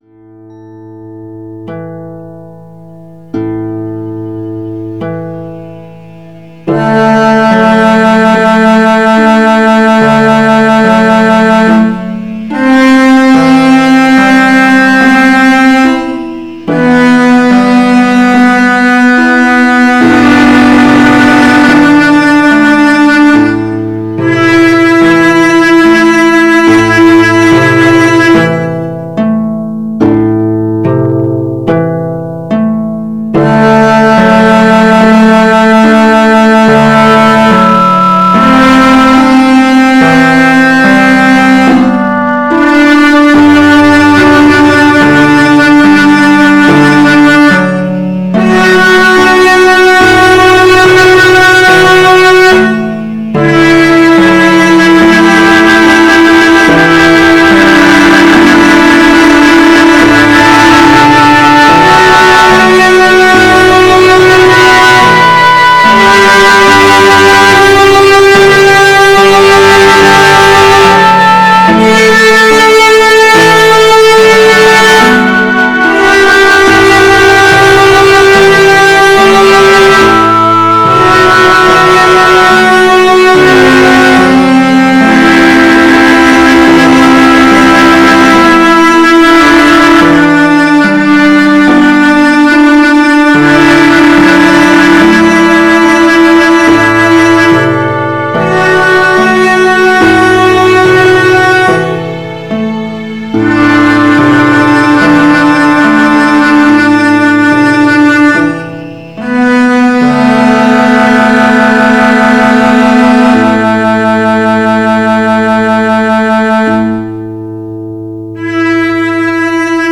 A Dorian, 72 BPM